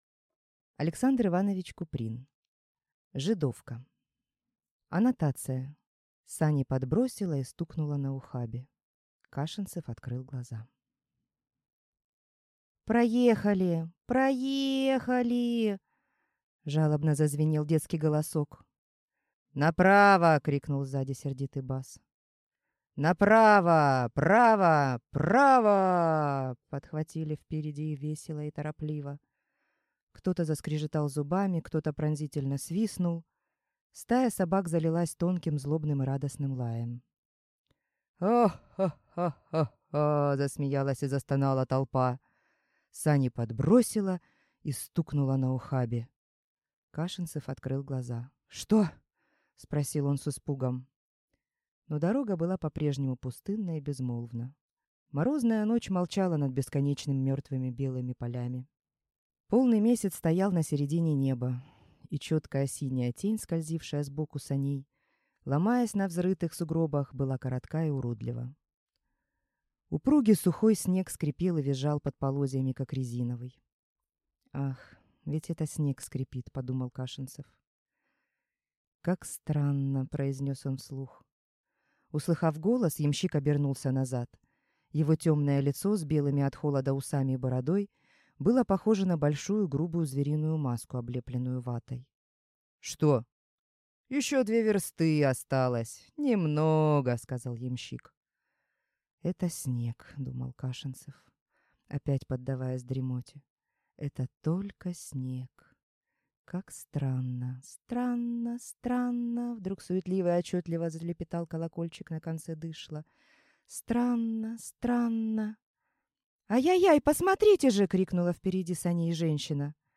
Аудиокнига Жидовка | Библиотека аудиокниг